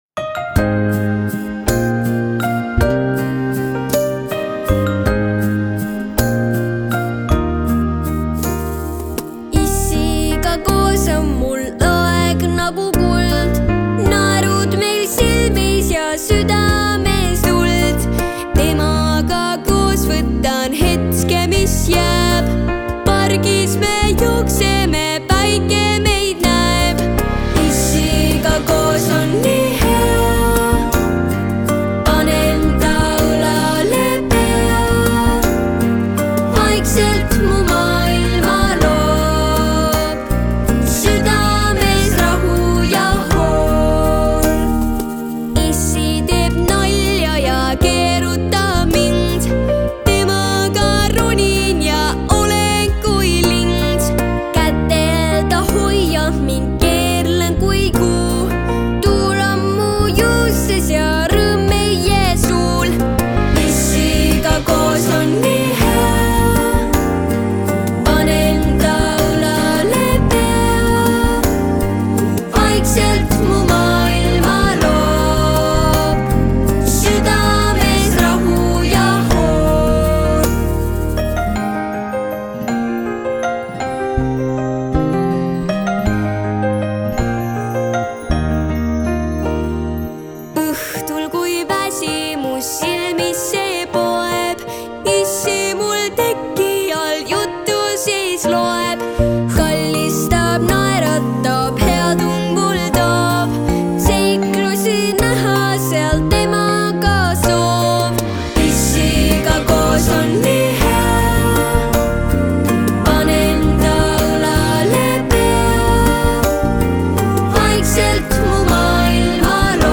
Originaalhelistik: Eb-duur Koosseis: solistile